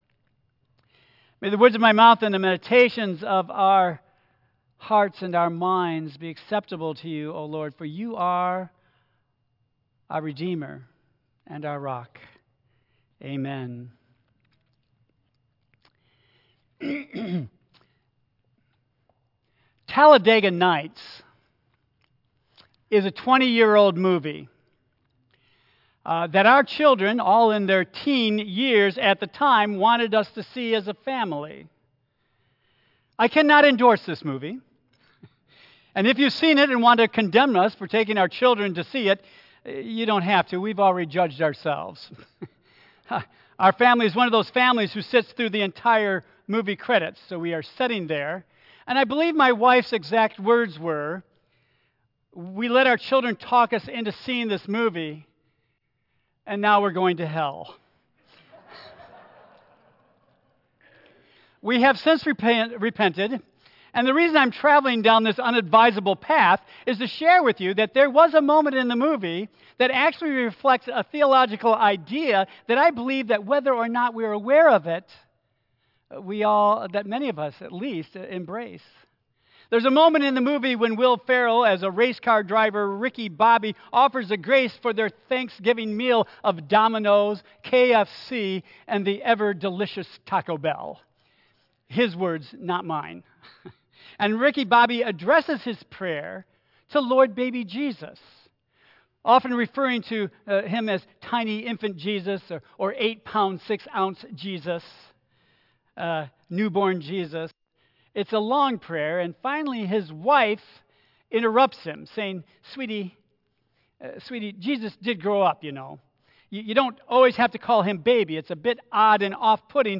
Tagged with 2025 , Advent , Michigan , Sermon , Waterford Central United Methodist Church , Worship Audio (MP3) 8 MB Previous Destination - Jerusalem and the First Temple Next Destination - Nazareth